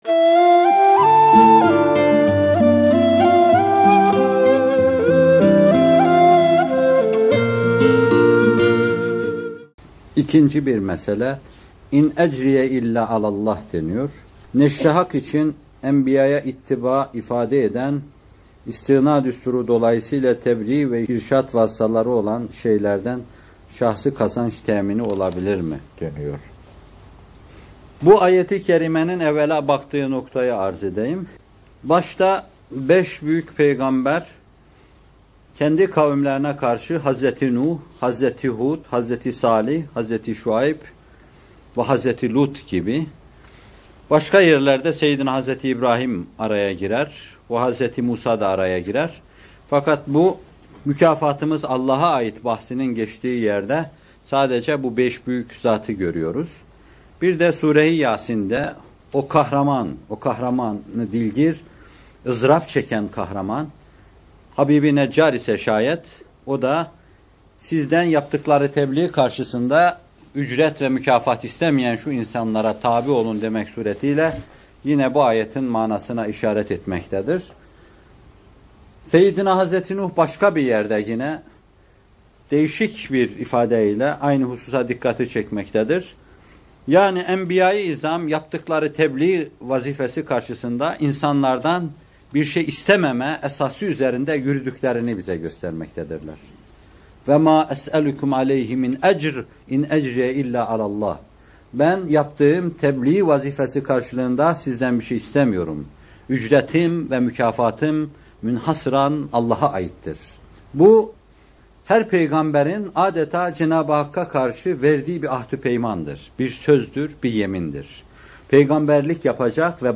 Muhterem Fethullah Gülen Hocaefendi bu videoda Yunus Suresi 72. ayet-i kerimesinin tefsirini yapıyor: